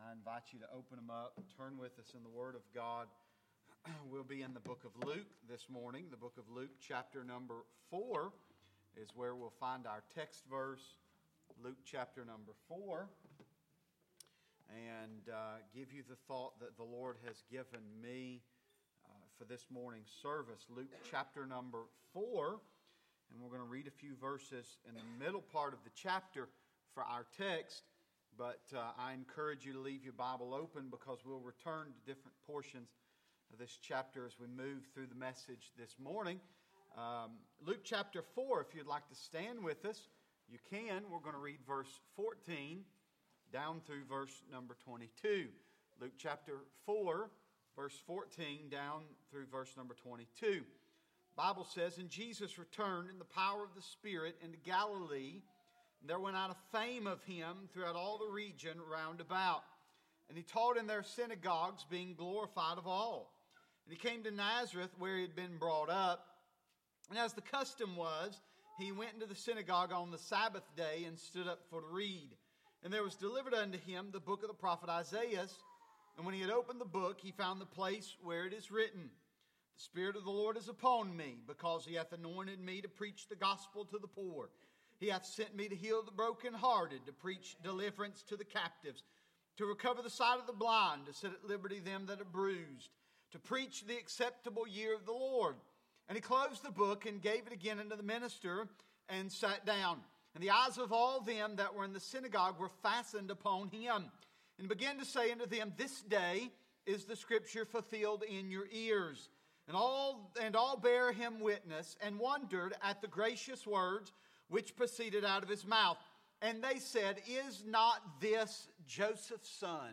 In this sermon, we learn about several different aspects of Jesus’ testimony, if He was to stand among the congregation and testify this day. We would be able to hear the testimony of suffering, the testimony of the sanctuary, and the testimony of the scriptures.